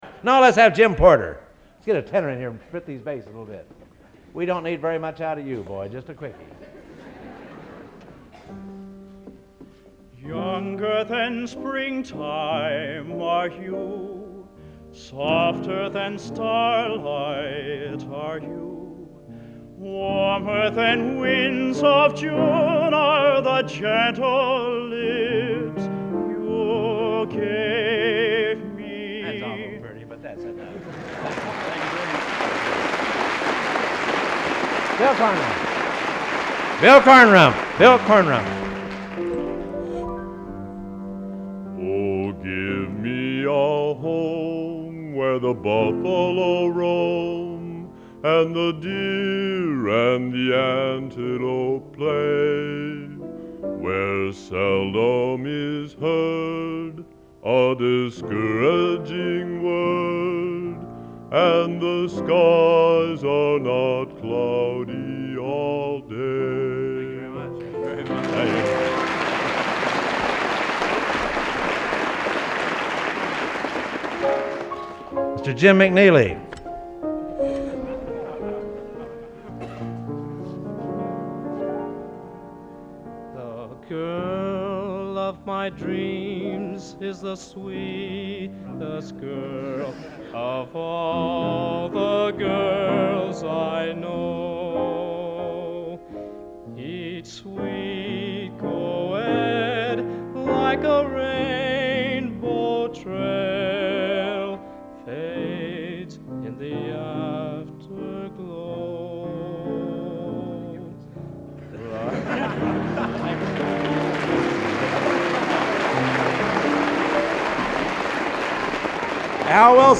Location: West Lafayette, Indiana
Genre: | Type: End of Season